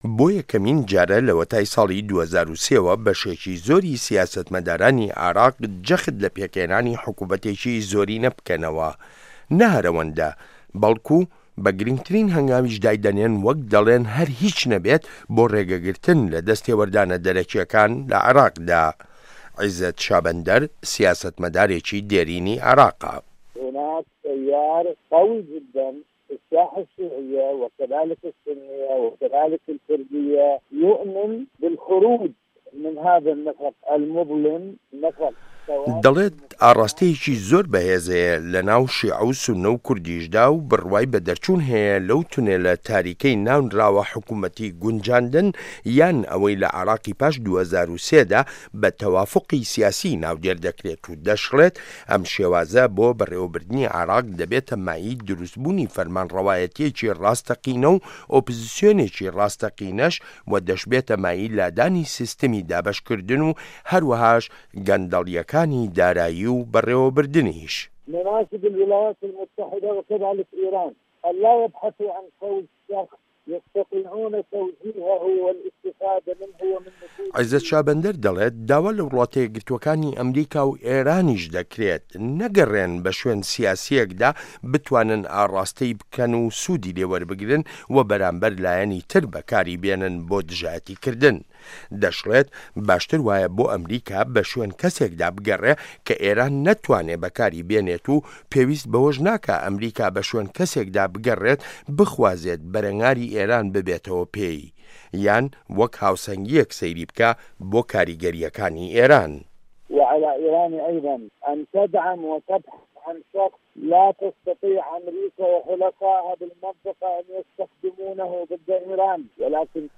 ڕاپۆرت لەسەر بنچینەی لێدوانەکانی عیزەت شابەندەر